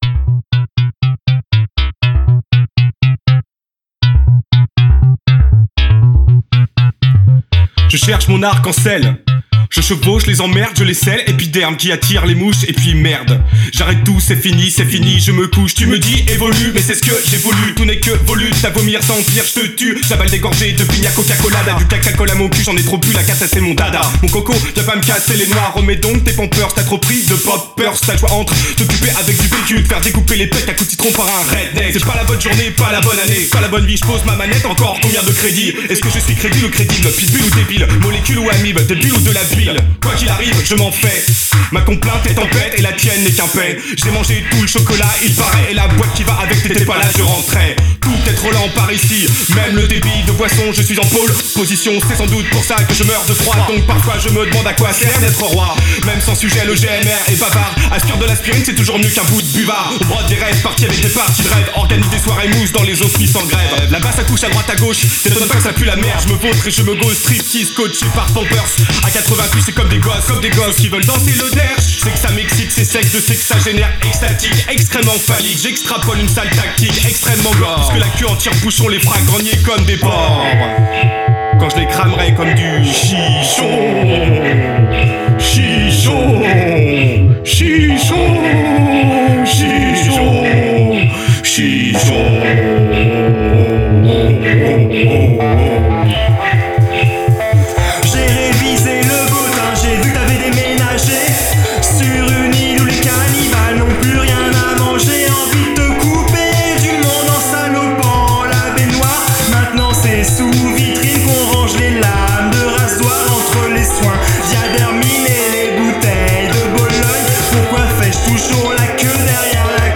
style: post-hop, IDM